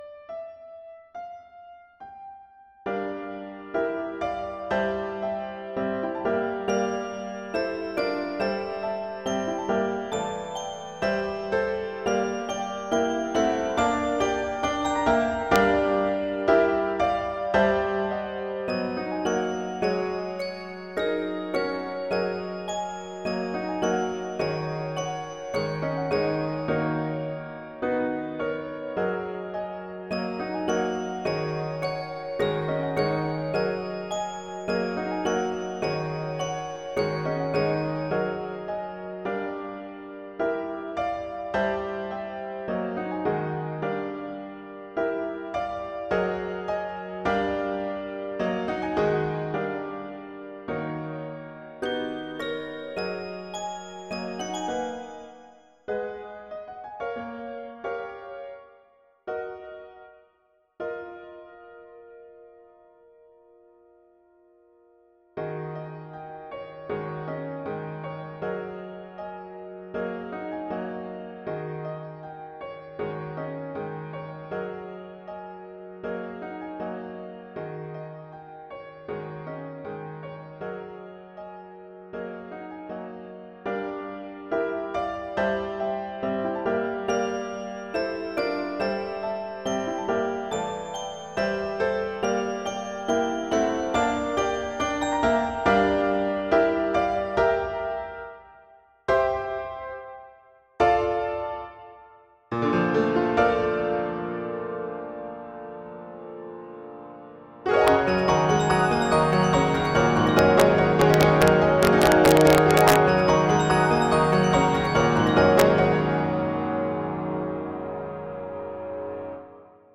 La progresión sigue este orden: Gm7, Am7, Gm7, Am7 invertido, Dm/A con E como novena y Gm7 con duplicación al final.
La caja de música es el acompañamiento.
Actualización: He visto buenos comentarios, y tengo aquí una actualización, hice como mas me gusta, he usado un estilo como tipo Debussy, ya que a mi me gusta desarrollar un ostinato a parte del tema central, en el final del 1er mov. termina en en el tema principal, el 2do solo da tensión y irresolución, espero que sea de agrado, el 2do tema tiene leimotiv anterior, aunque lo he ligeramente ajustado, buenas días allá en España y que sea de vuestro gusto
La intro suena prometedora, no sabría decirte como mejorarla (si es que lo necesita), pero sí, apunta a una pieza del estilo del maestro Hisaishi...